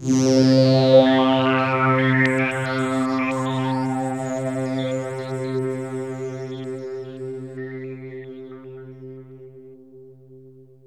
AMBIENT ATMOSPHERES-5 0004.wav